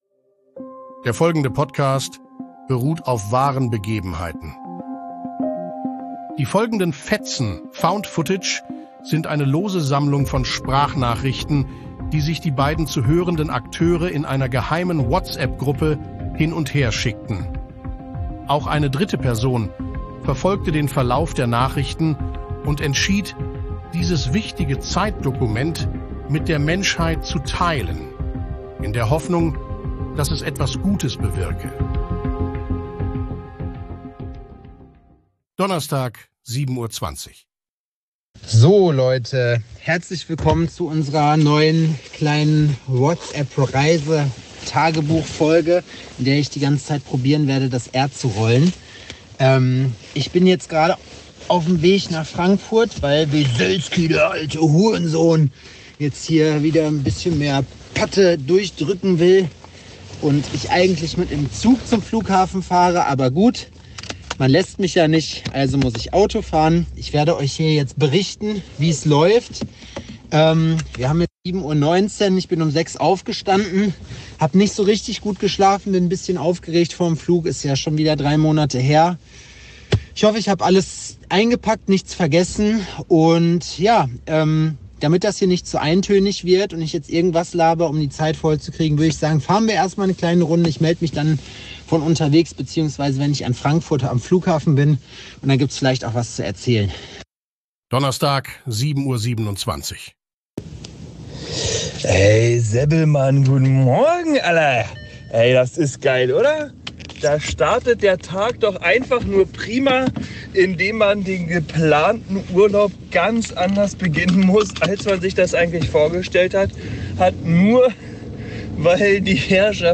Es ist mal wieder Zeit für Sprachnachrichten. Ein Tagebuch von Zweien, deren Wochenende nicht unterschiedlicher hätte sein können.